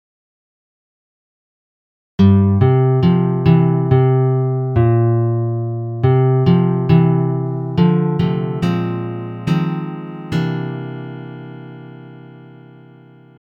Key written in: C Major
How many parts: 4
Type: Other male
All Parts mix: